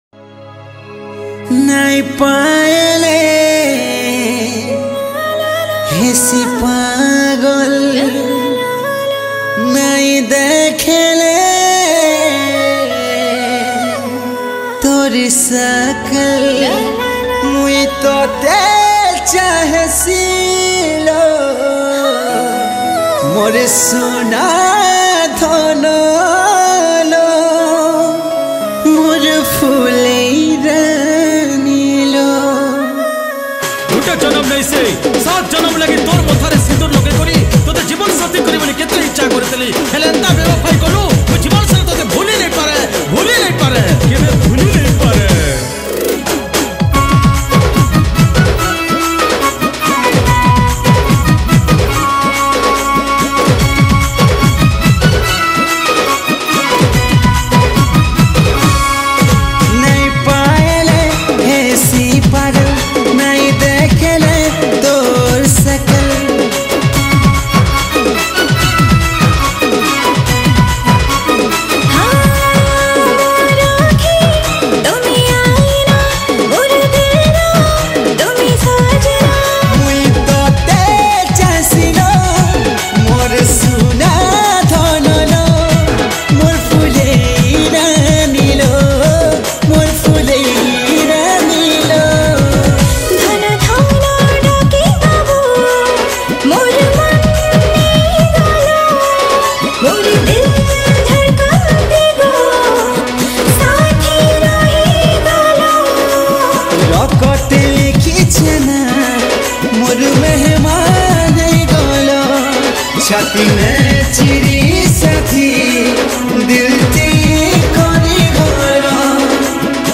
Category: New Sambalpuri Songs 2022